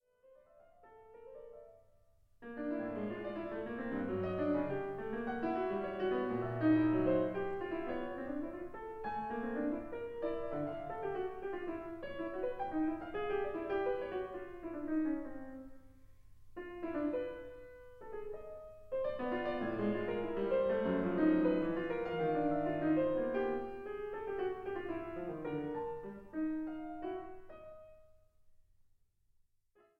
アコースティック
アバンギャルド
インストゥルメンタル
録音・編集も、ライブの緊迫した臨場感を伝えつつ、クオリティの高い仕上がり。
ピアノソロ